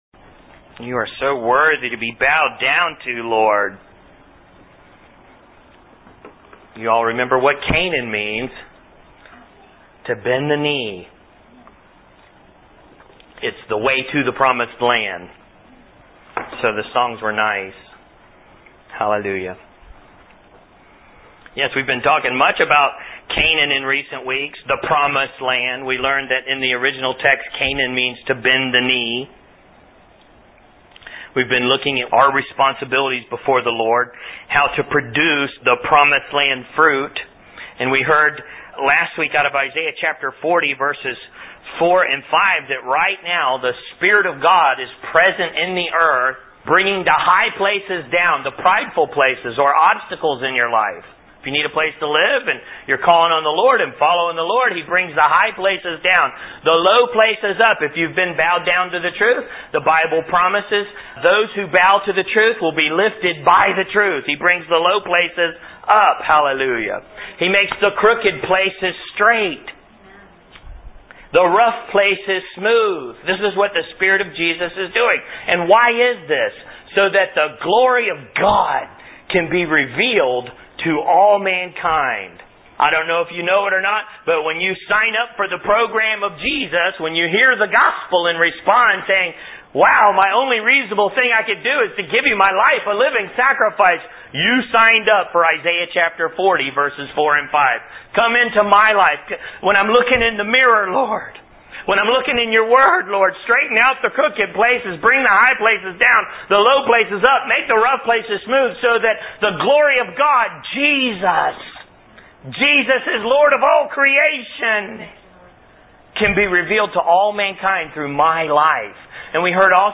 Taking Canaan, full message (53 minutes)